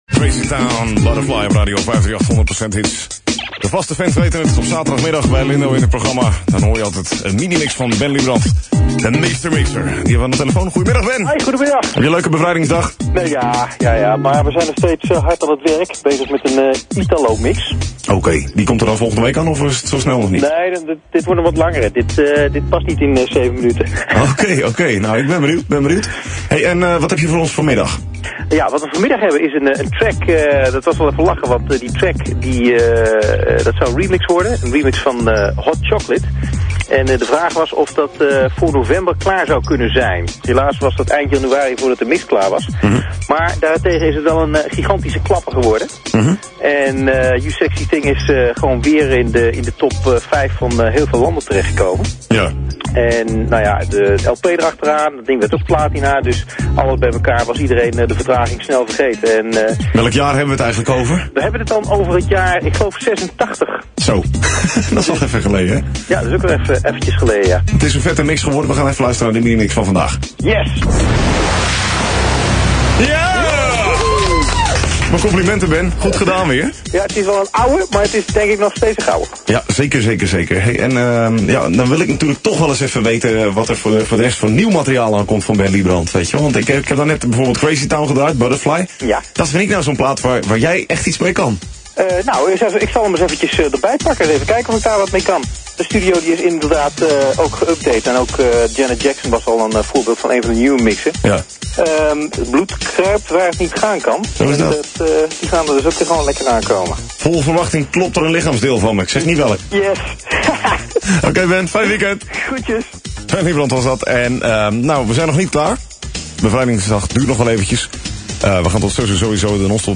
Een geluidsclip van het voorgesprek is beschikbaar.